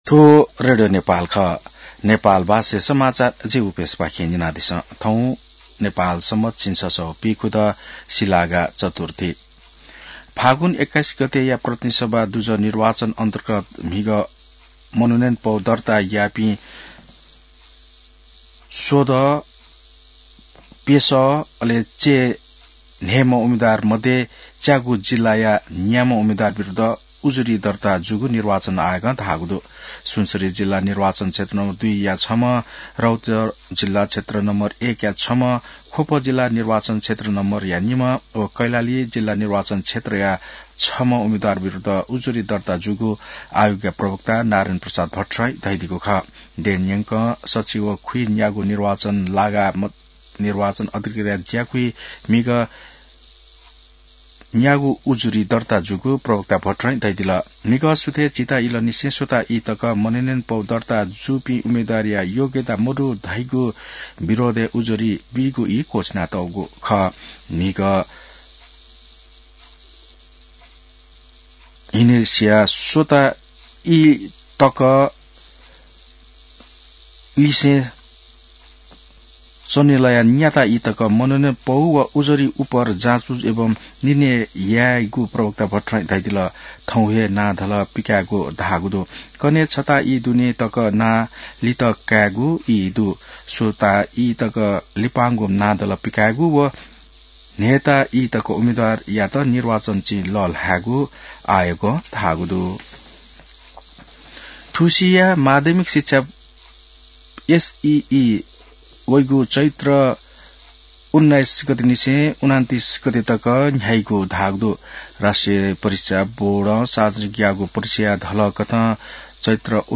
नेपाल भाषामा समाचार : ८ माघ , २०८२